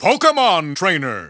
The announcer saying Pokémon Trainer's name in English releases of Super Smash Bros. Brawl.
Pokémon_Trainer_English_Announcer_SSBB.wav